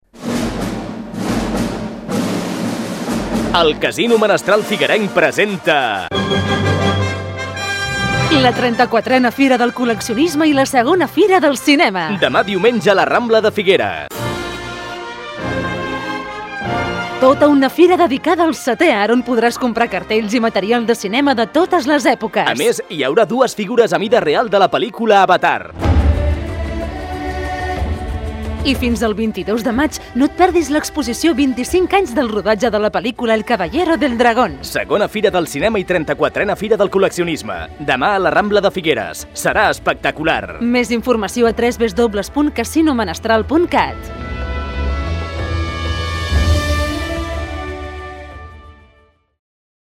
Spot de ràdio anunciant la Fira: